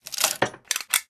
repair1.ogg